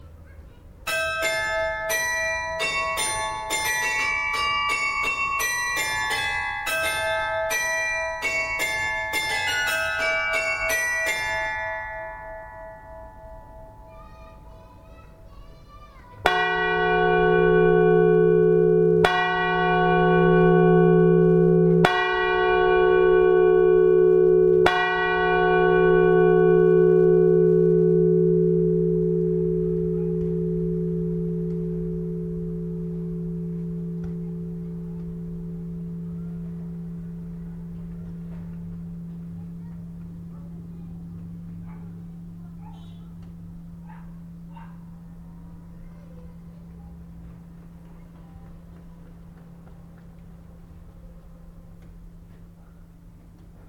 (2) La ritournelle.